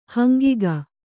♪ hangiga